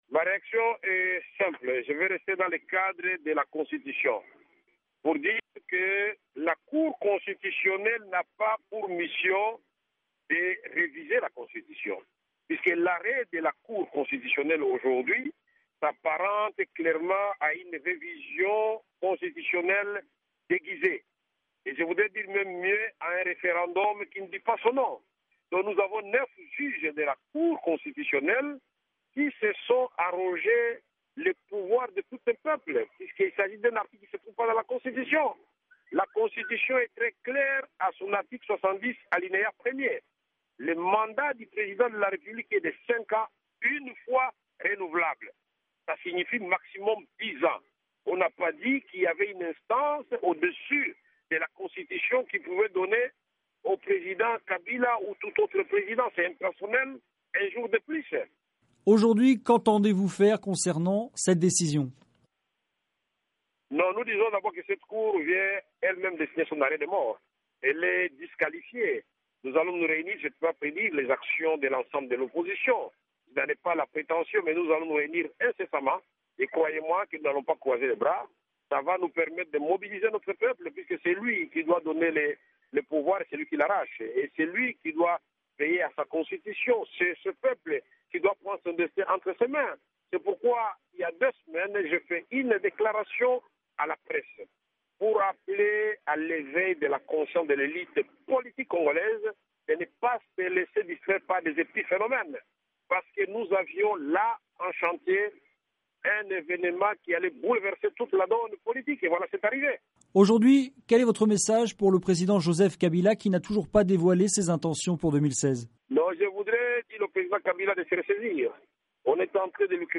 Le président congolais Joseph Kabila pourra rester en fonctions au-delà du terme de son mandat, fin 2016, si l'élection présidentielle censée avoir lieu cette année n'a pas lieu, selon un arrêt rendu par la Cour constitutionnelle. Le president de l'UNC réagit sur VOA Afrique.